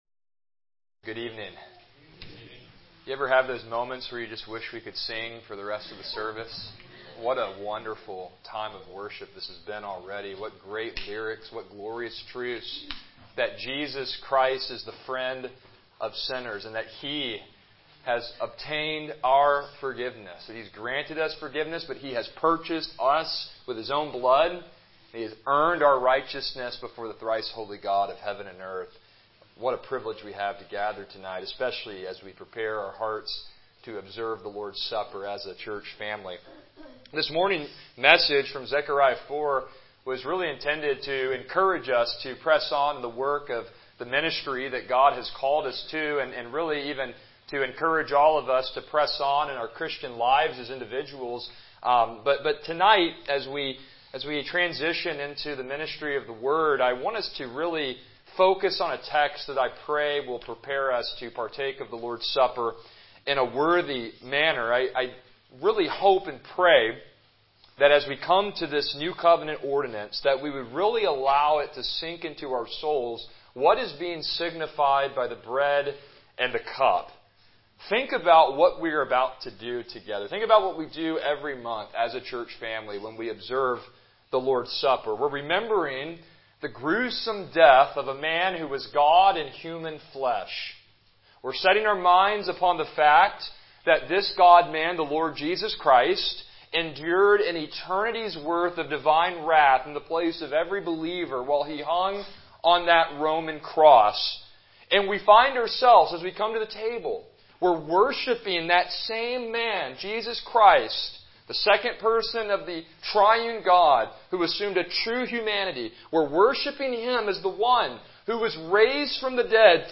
John 19:16-30 Service Type: Evening Worship « Not by Might